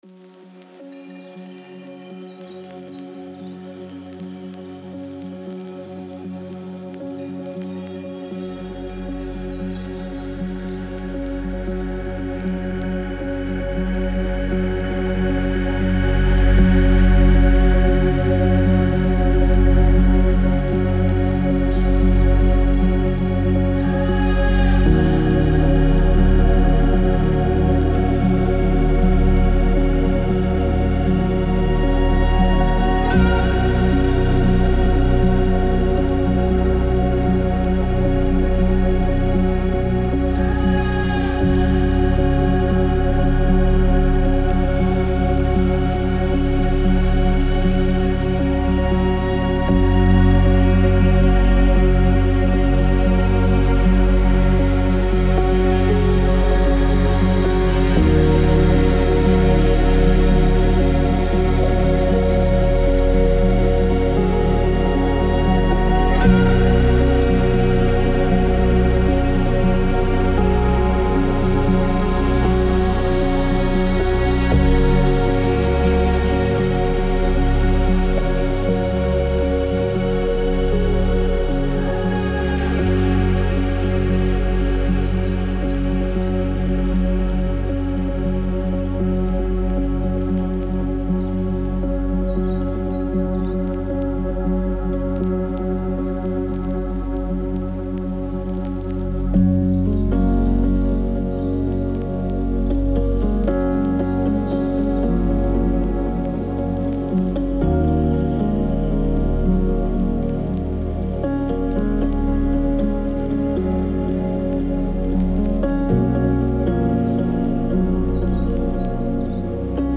موسیقی کنار تو
سبک داون‌تمپو , موسیقی بی کلام
موسیقی بی کلام داون تمپو موسیقی بی کلام دیپ